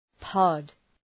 Προφορά
{pɒd}